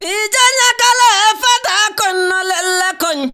VOC 06.AIF.wav